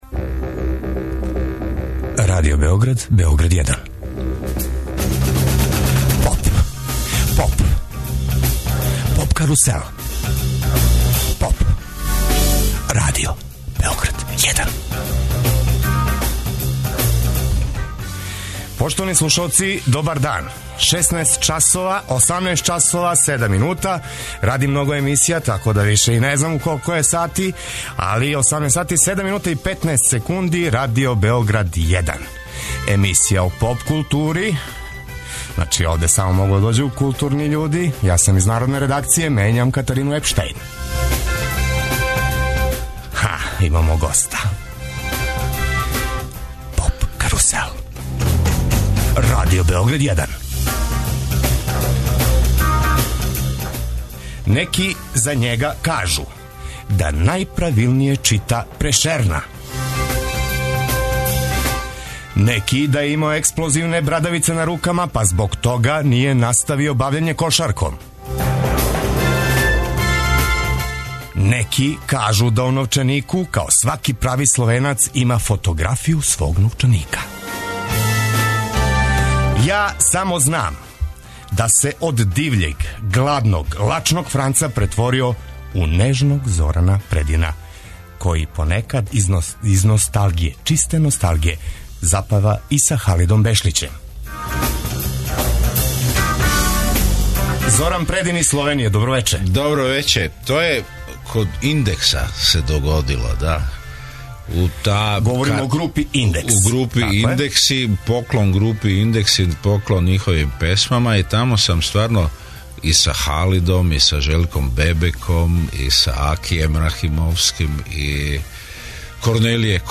Наш данашњи гост је Зоран Предин. Овај словеначки кантаутор, коме због његових текстова пуних еротике и црног хумора чак и највећи супарници признају духовиту ауторску оригиналност и музичку наконвенционалност, окупио је нови бенд и најавио концерт у Београду 14. фебрауара.